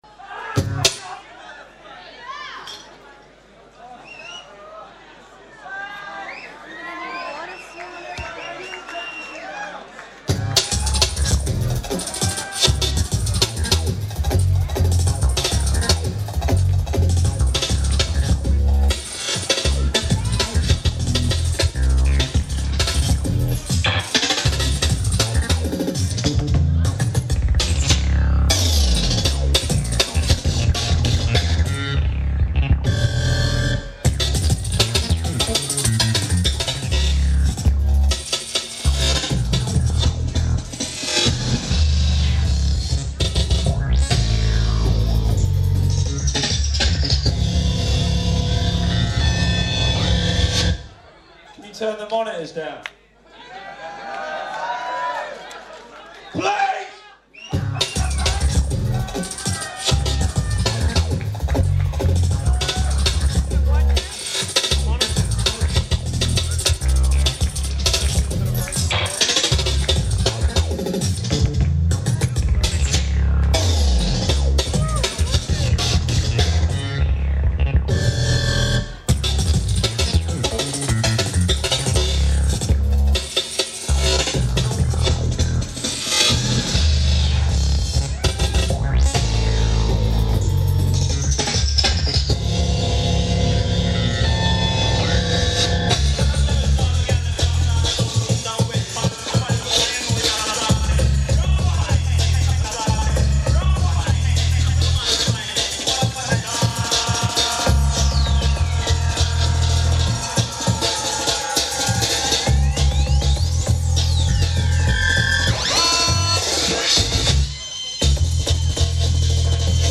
venue Belongil Fields (Byron Bay)